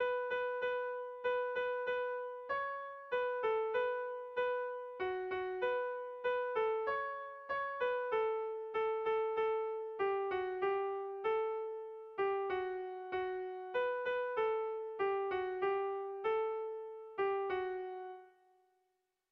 Haurrentzakoa
Lauko handia (hg) / Bi puntuko handia (ip)
AB